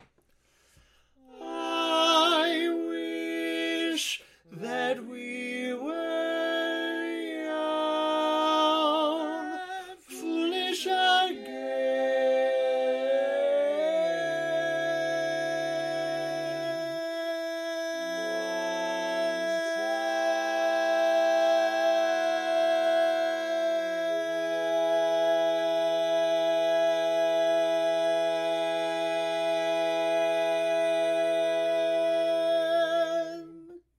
Key written in: E Major
Type: Barbershop